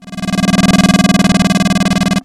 游戏音频 UI SFX " Teleport Morphy
描述：传送到经线区域的瞬间传送。所有这些都是从头开始创建的，并使用Pro Tools内部的各种工具以及旧键盘（Ableton和Surge）进行分层
Tag: 瞬间移动 翘曲 SCI 科幻